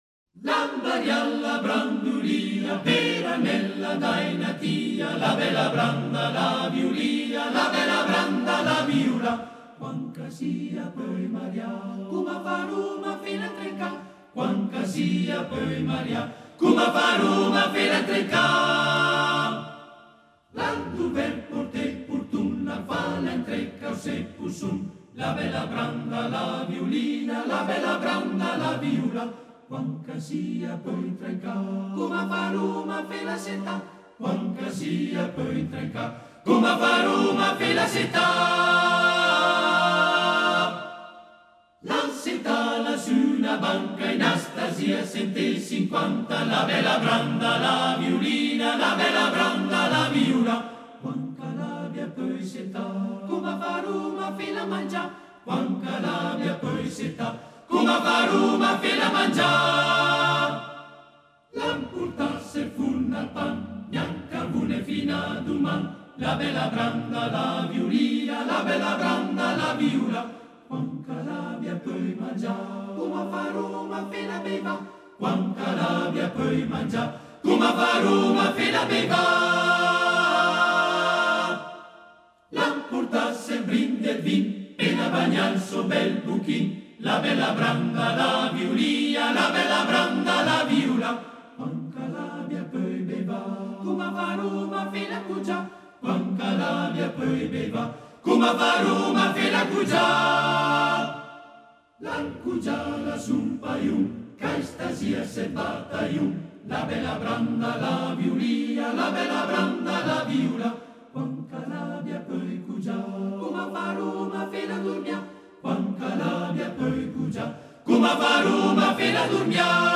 Arrangiatore: Benedetti Michelangeli, Arturo (armonizzatore)
Esecutore: Coro della SAT